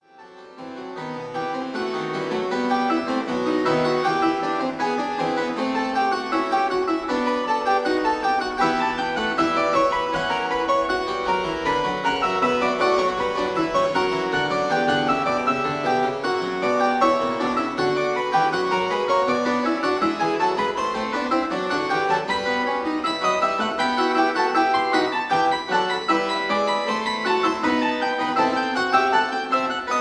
I: 16,8,4 leather; II: 8 quill, 8 lute